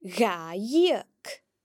If dh is used before a broad vowel (a, o, or u), it is pronounced similarly to the English ‘g’, as can be heard in dhà (the dependent form of , two):